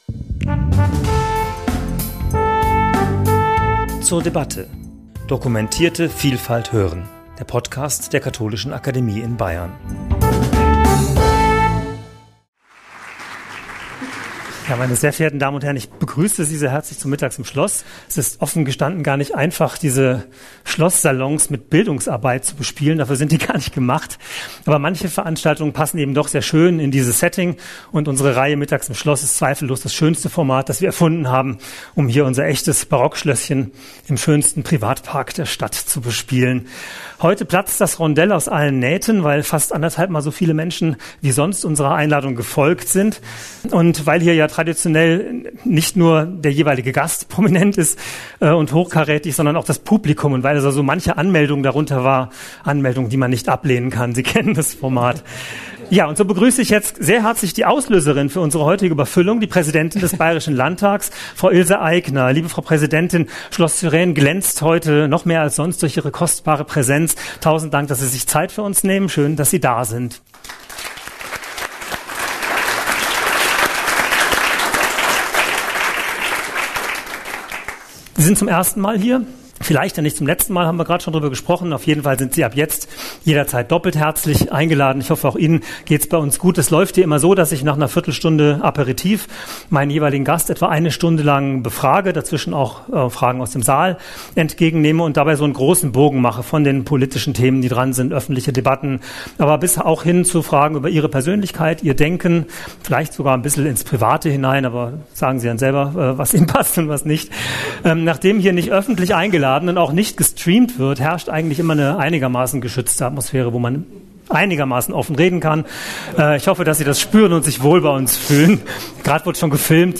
Beschreibung vor 1 Jahr In der Katholischen Akademie in Bayern bricht Ilse Aigner am 30.4.2024 eine Lanze für Demokratie und Parlamentarismus. Die Präsidentin des Bayerischen Landtags, Ilse Aigner, war zu Gast bei 'Mittags im Schloss'.